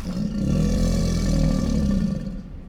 wildlife_crocodile.ogg